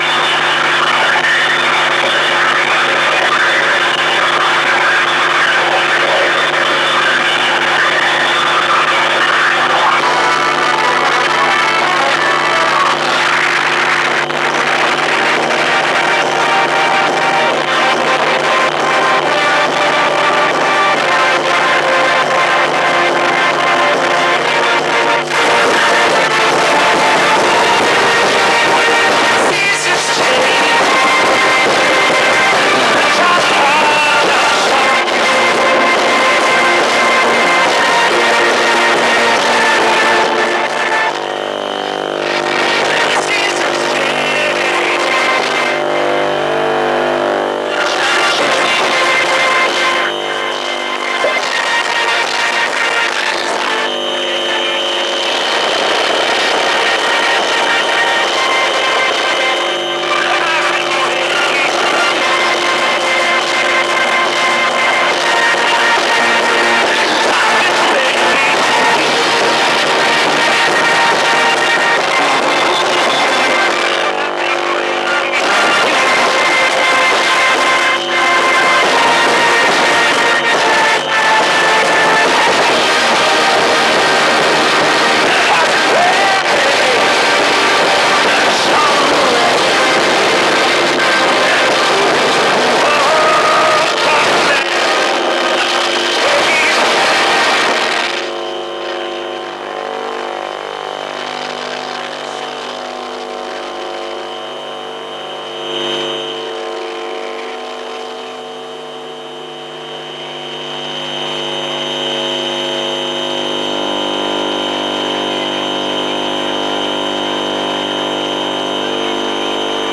induction loop with Dlan
induction-loop-with-Dlan-8.mp3